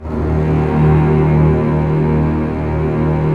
Index of /90_sSampleCDs/Roland - String Master Series/STR_Cbs Arco/STR_Cbs2 Orchest